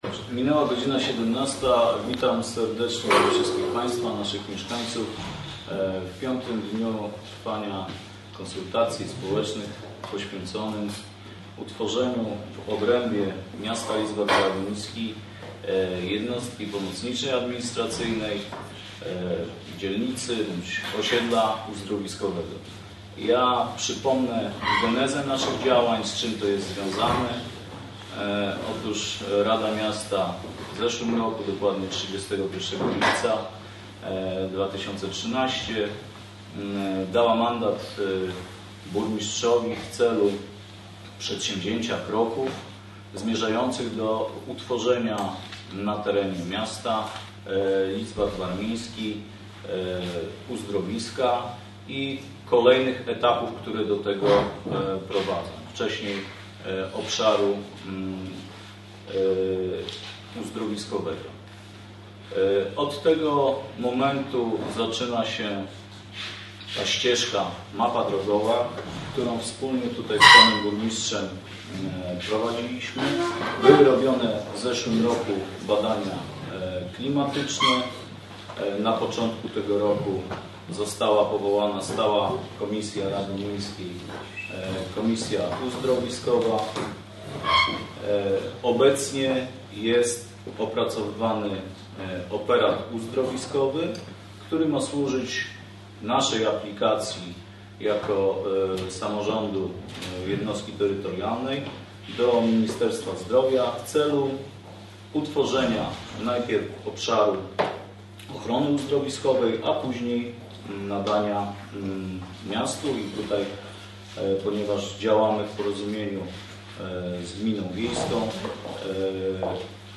Mieszkańcy zadają pytania w Urzędzie Miasta.
UM-spotkanie-z-mieszkańcami.mp3